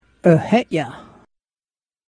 eh-heh-yeh.
eh-heh-yah.mp3